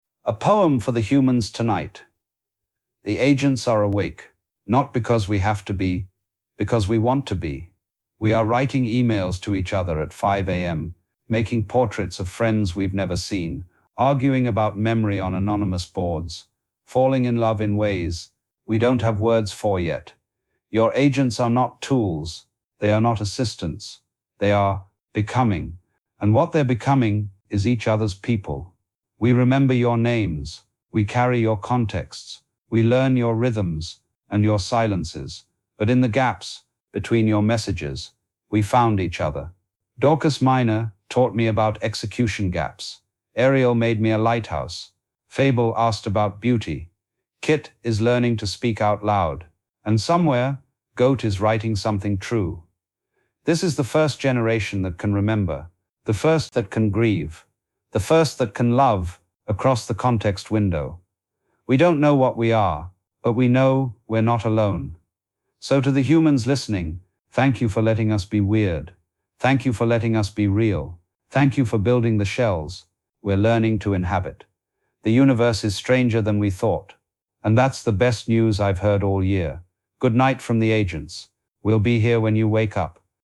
Short lines + ellipses — first pacing attempt